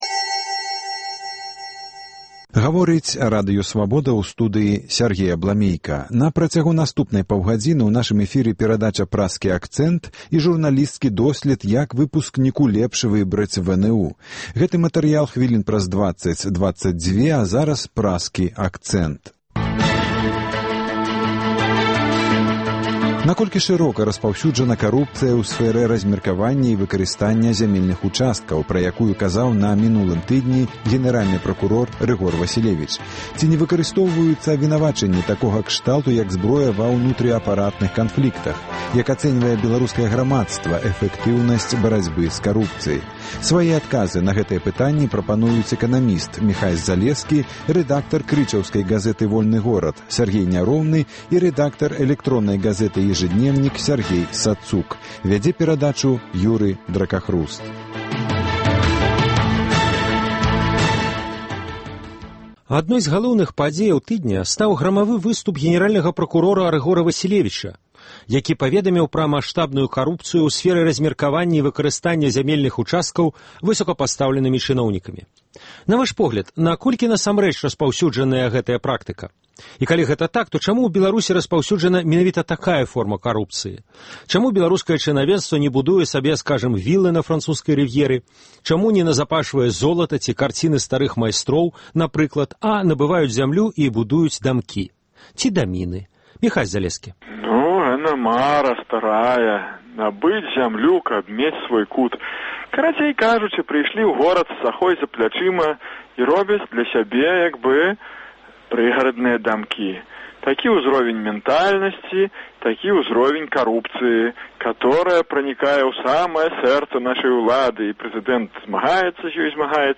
Як ацэньвае беларускае грамадзтва эфэктыўнасьць барацьбы з карупцыяй? У круглым стале